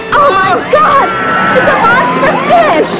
Not much later, the Beastie puts in an appearance at the beach, prompting someone to scream,